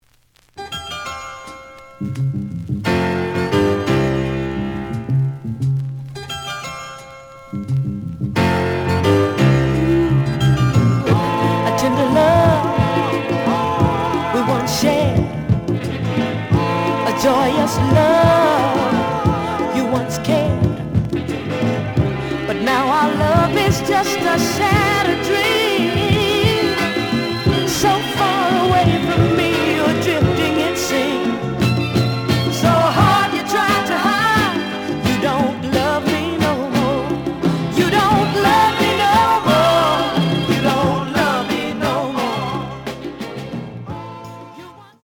The audio sample is recorded from the actual item.
Looks good, but slight noise on both sides.)